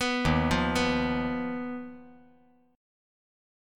D#sus2#5 Chord
Listen to D#sus2#5 strummed